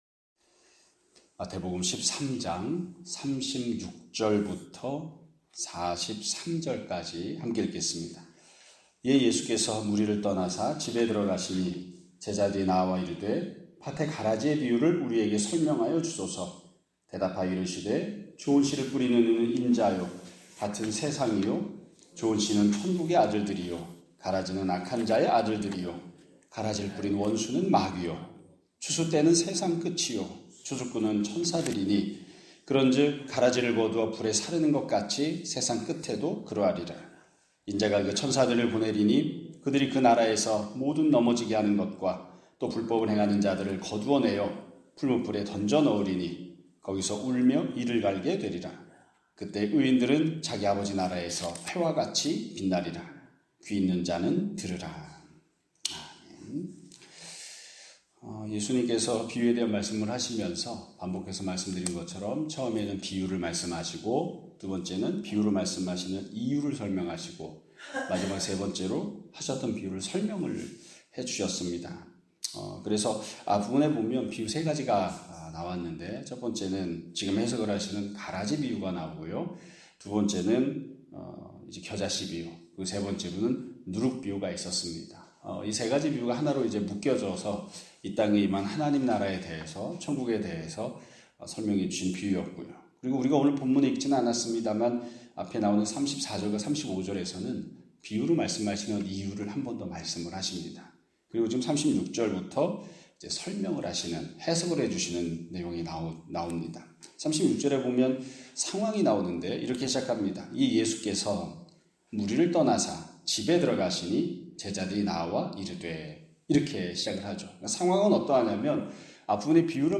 2025년 10월 1일 (수요일) <아침예배> 설교입니다.